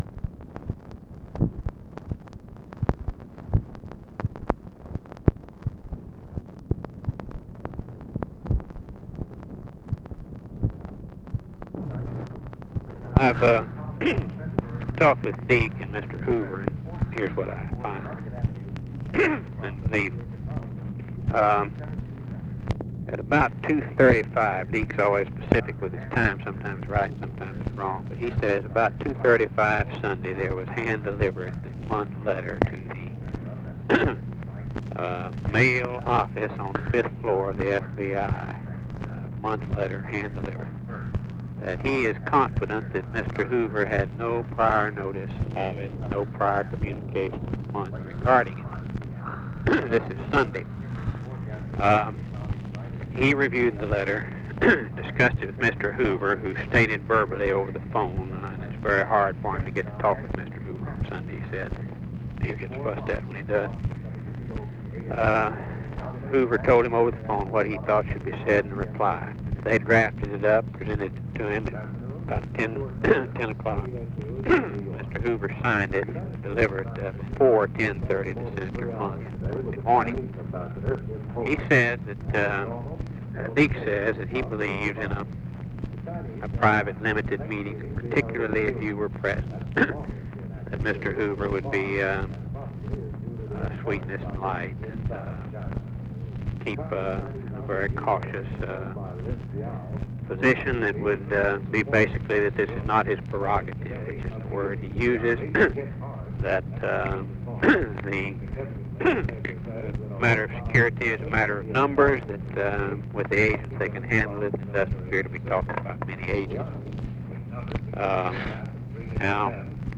Conversation with RAMSEY CLARK, January 25, 1967
Secret White House Tapes